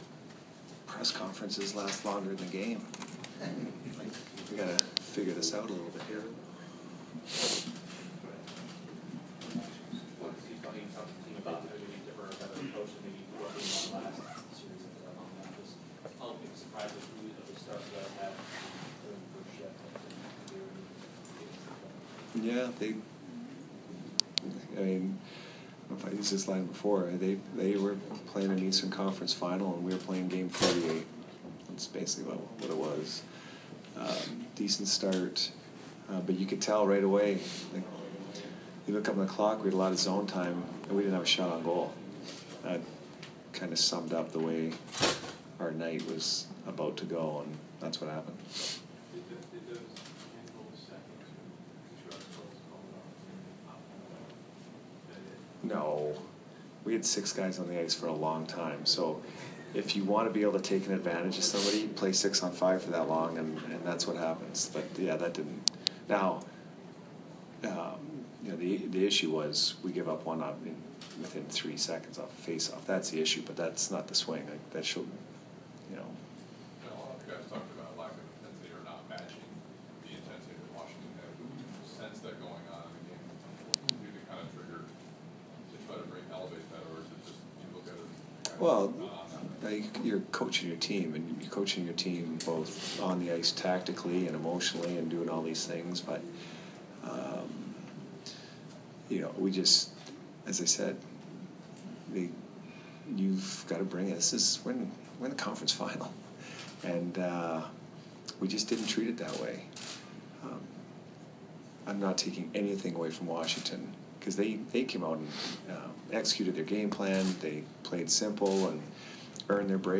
Jon Cooper post-game 5/11